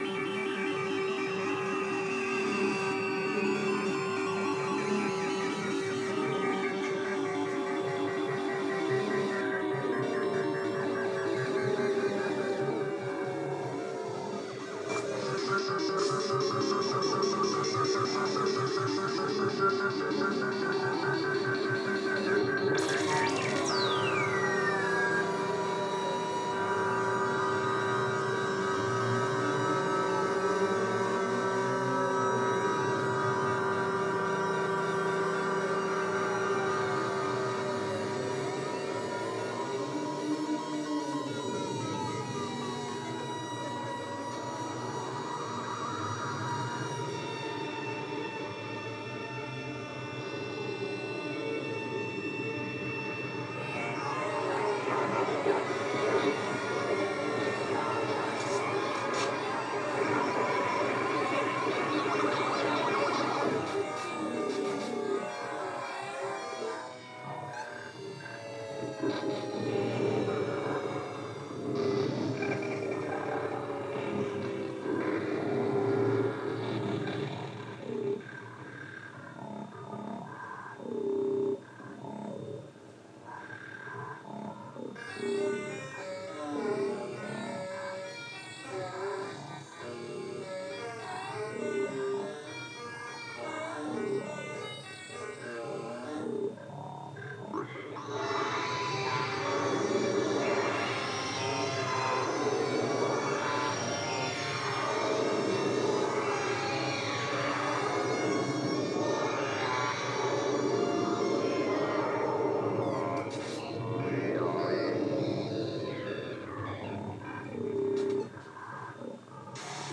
Patchwerk modular synthesizer MIT